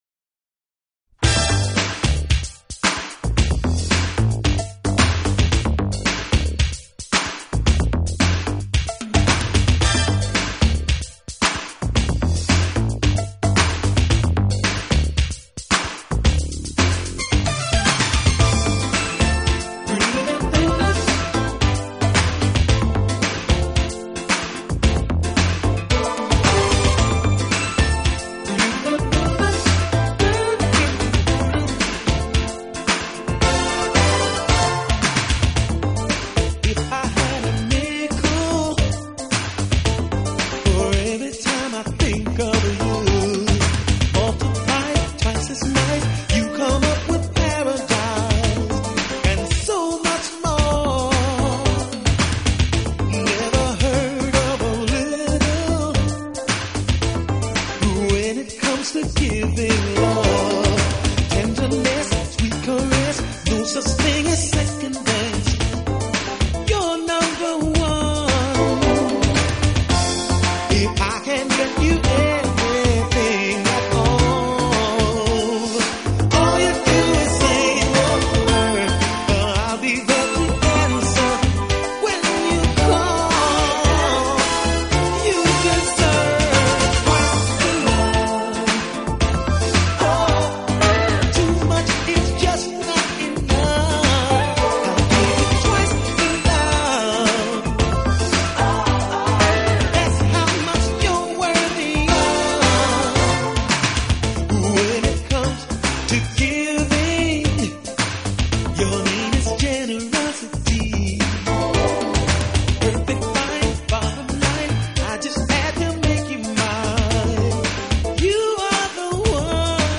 Jazz Guitar+Vocal